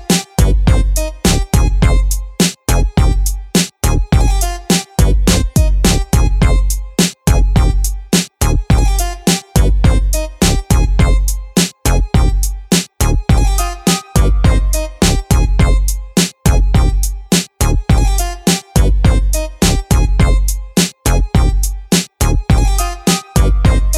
no FX Pop (2010s) 4:24 Buy £1.50